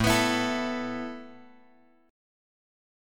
Asus2 chord {5 x x 4 5 5} chord
A-Suspended 2nd-A-5,x,x,4,5,5.m4a